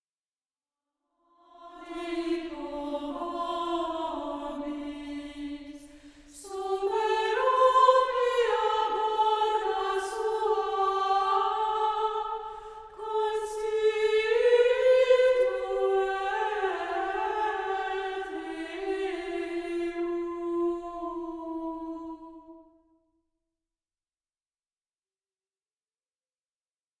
Communion